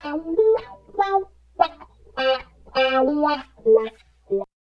70 GTR 5  -R.wav